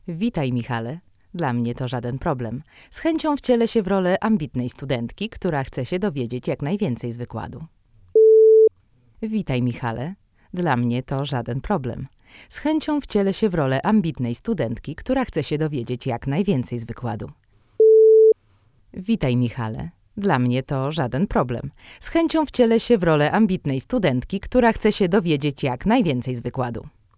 Przygotowaliśmy po dwa pliki dla każdego z tych lektorów i dodatkowo, dwa pliki obrazujące zmiany w brzmieniu, wywołane przez gałkę HMX w pozycji 0, w połowie skali i na maksymalnym poziomie.